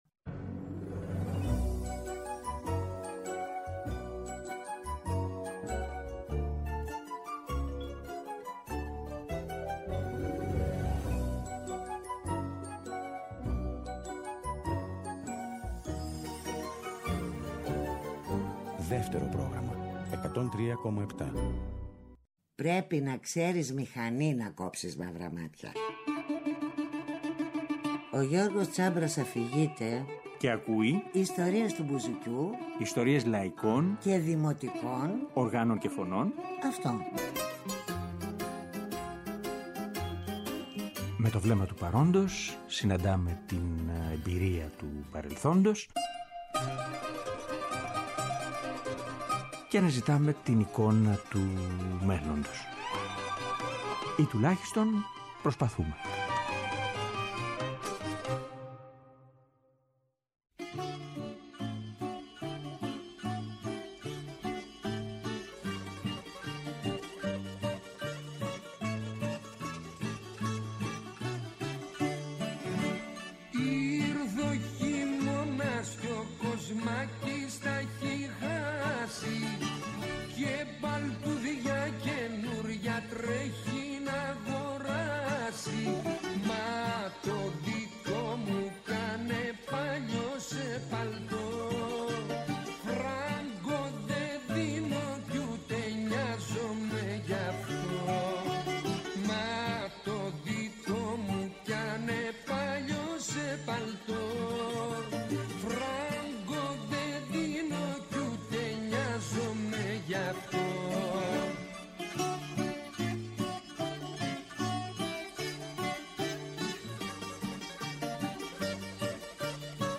Τραγούδια στον απόηχο του σμυρνέικου, τραγούδια με τον ήχο του ρεμπέτικου.
Στην εκπομπή, εναλλάσσονται οι εποχές, οι συνθήκες, τα πρόσωπα και οι εκτελέσεις όλων αυτών των χρόνων.